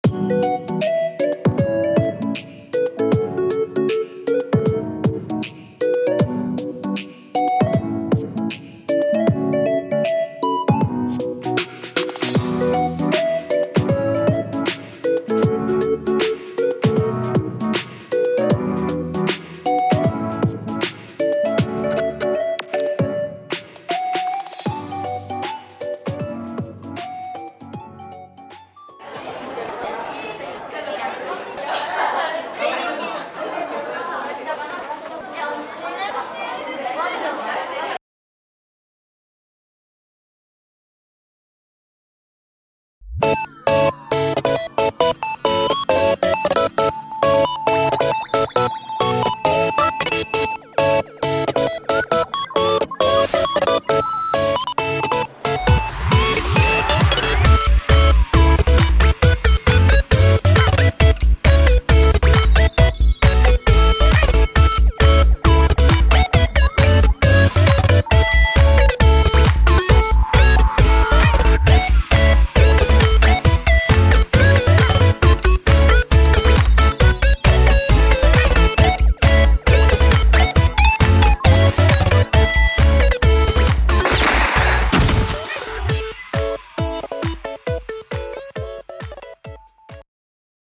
声劇【それ反則です！伊波君】